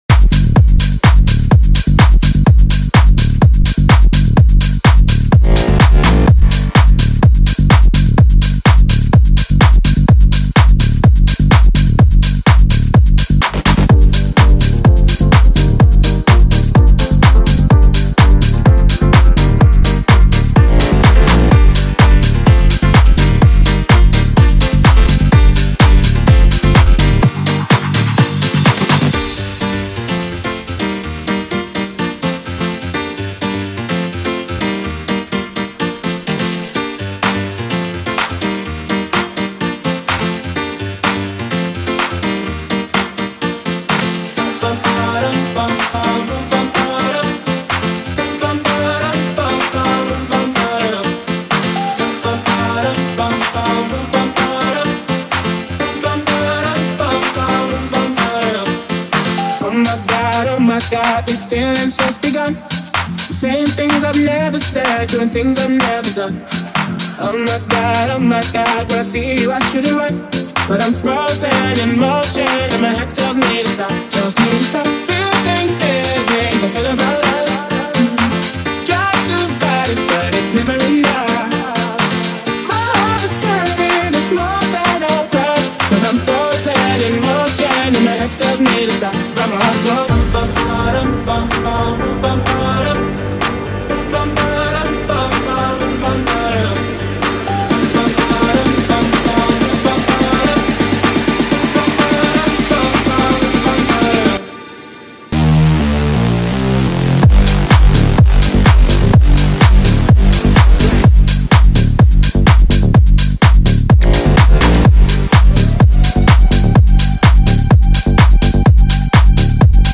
Also find other EDM Livesets, DJ Mixes